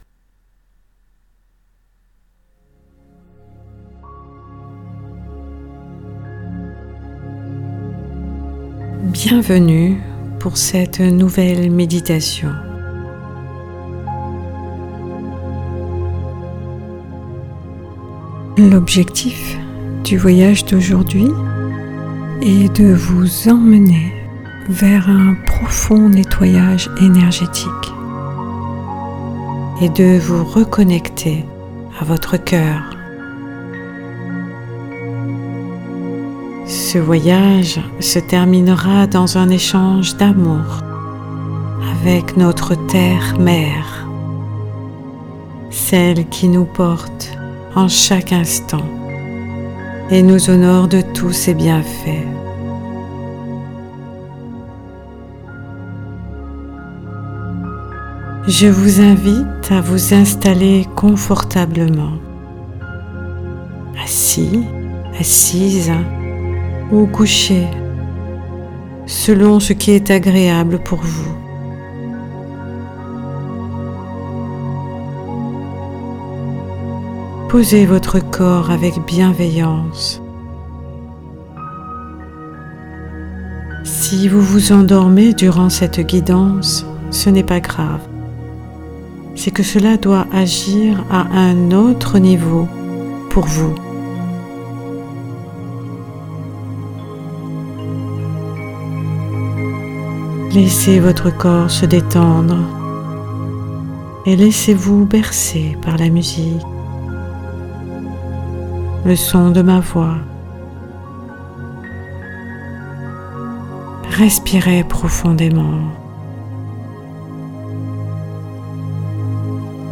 Découvrez une partie de mon travail à travers cette méditation
meditation-nettoyage-energetique.mp3